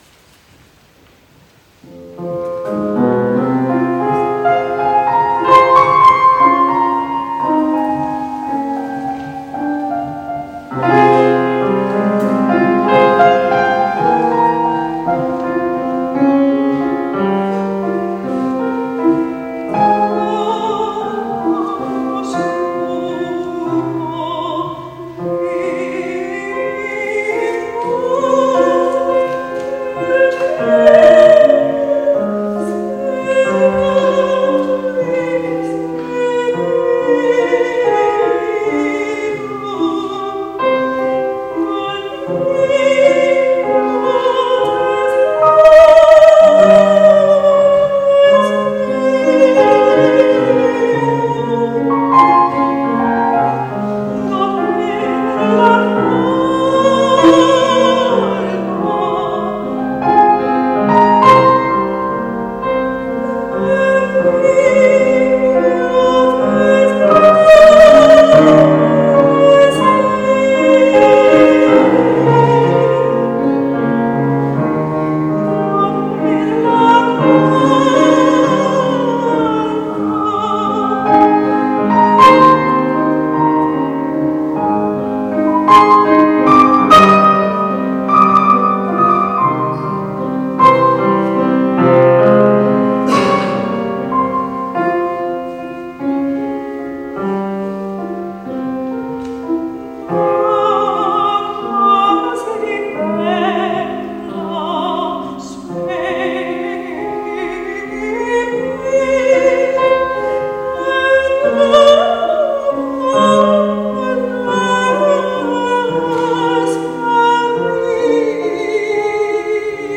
live in concert, selected arias
soprano
piano - live in concert 2023